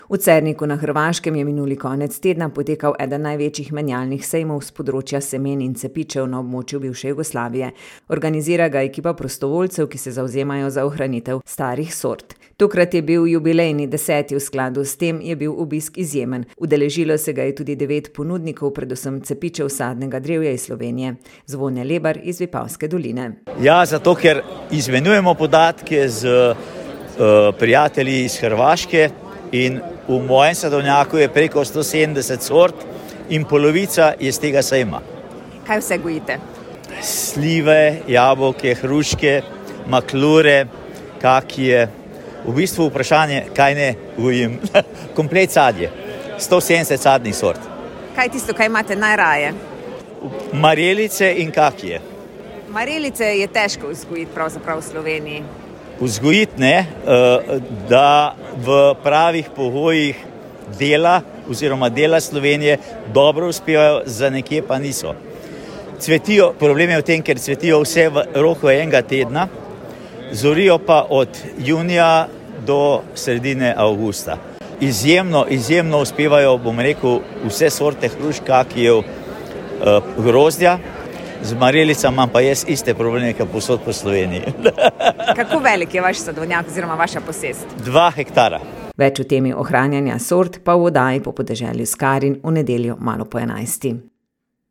Bili smo v Cerniku